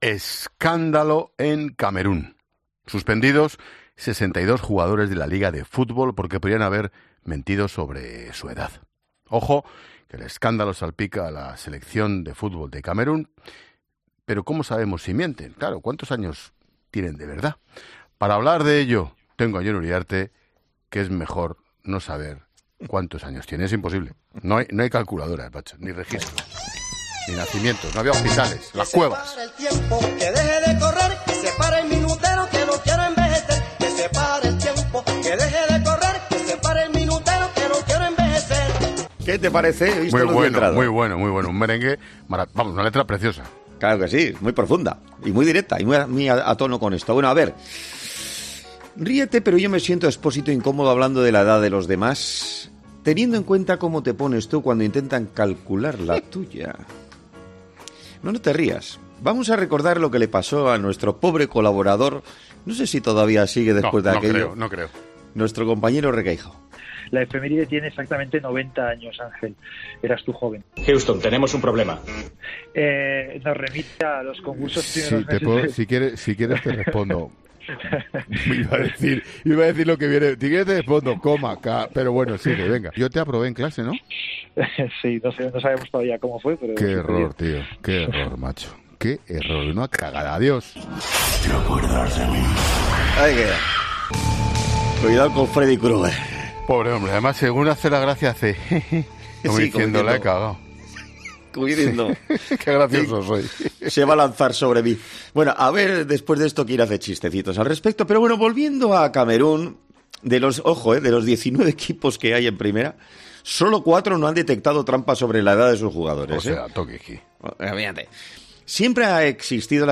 Un caso que ha hecho estallar de la risa al director de La Linterna, Ángel Expósito, que alucinaba con el “truco” del futbolista para esconder su edad: “¡Era el mismo el tío! Que heavy”.